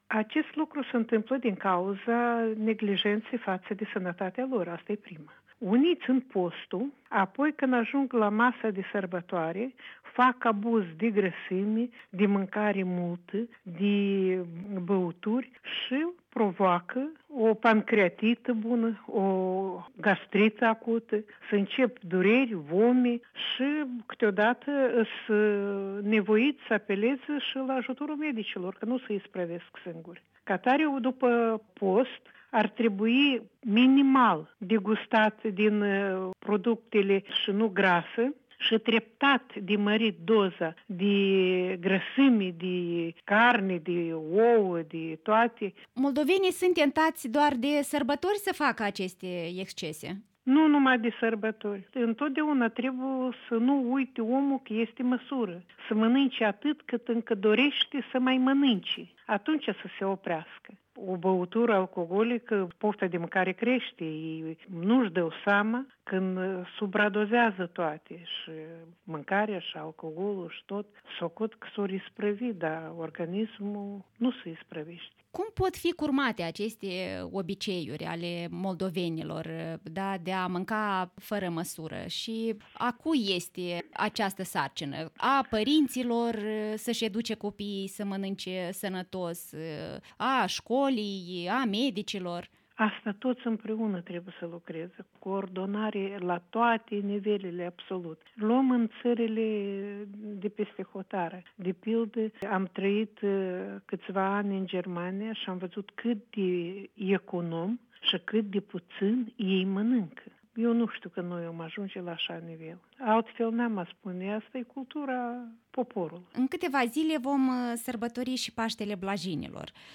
Interviurile Europei Libere